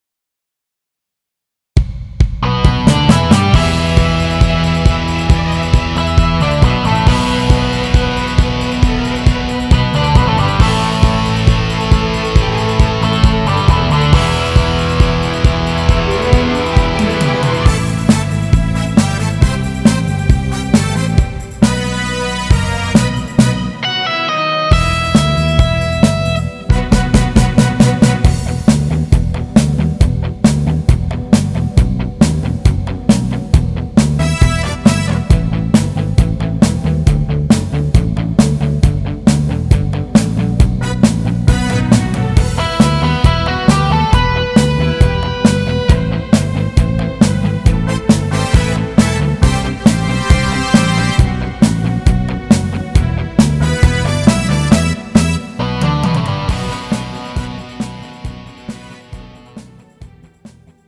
음정 남자-1키
장르 가요 구분 Pro MR